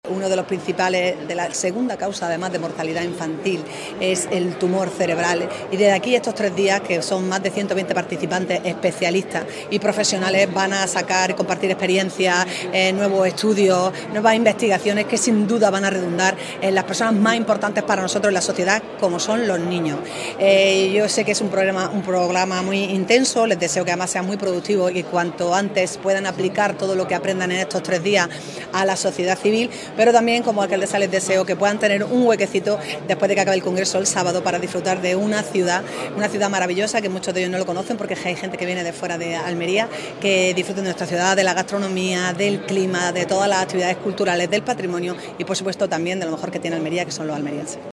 Alcaldesa, delegada del Gobierno y vicepresidente de la Diputación inauguran este encuentro que, bajo el lema ‘Nuestros niños, nuestros pacientes, nuestra razón de ser’, se celebra del 19 al 21 de febrero
ALCALDESA-CONGRESO.mp3